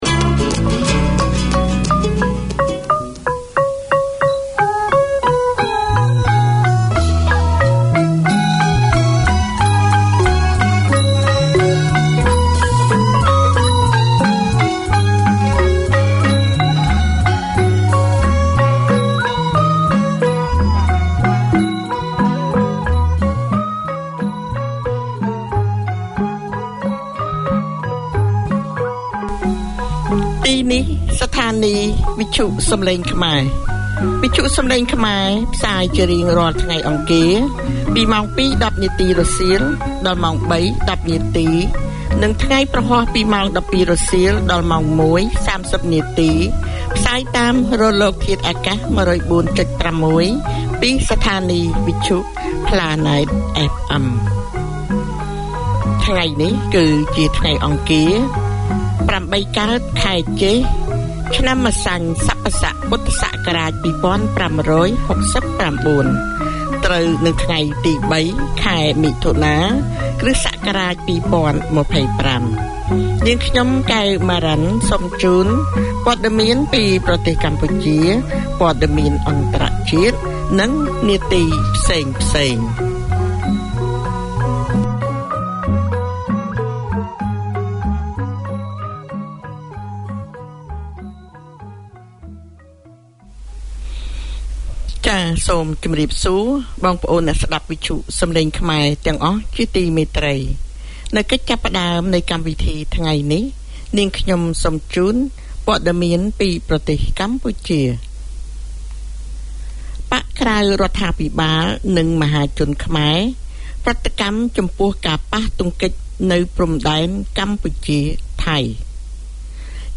It is both a reflection of the Khmer community in Auckland and an avenue for new Cambodian migrants. Music mixes with news local and global, interviews, religious topics, settlement topics and issues, with talkback.